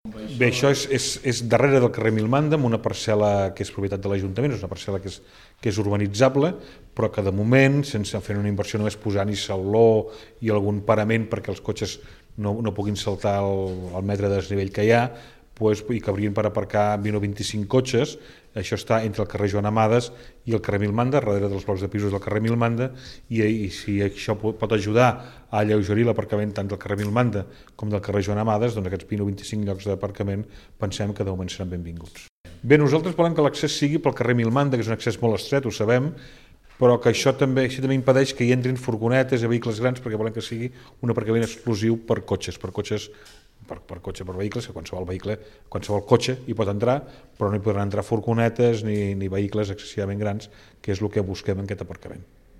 ÀUDIO: El regidor de Serveis Municipals planteja el nou aparcament